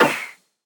Minecraft Version Minecraft Version snapshot Latest Release | Latest Snapshot snapshot / assets / minecraft / sounds / mob / breeze / deflect1.ogg Compare With Compare With Latest Release | Latest Snapshot
deflect1.ogg